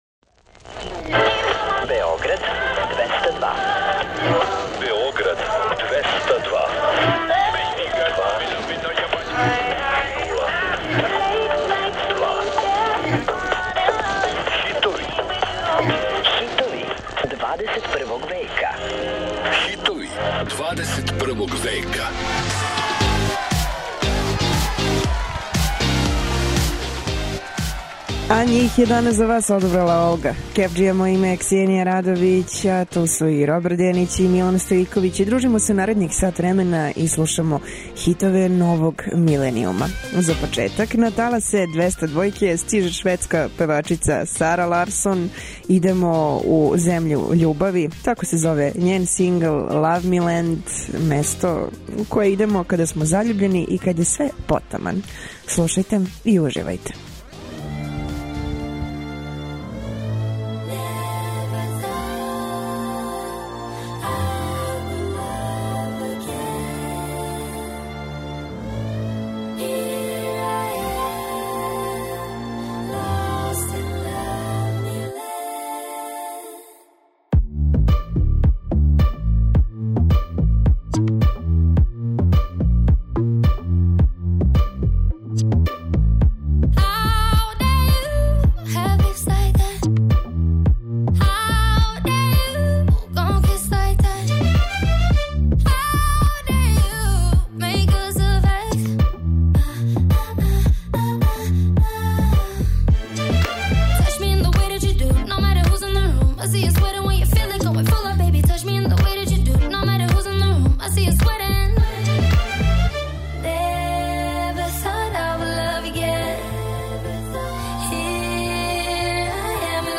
Очекују вас највећи хитови 21. века!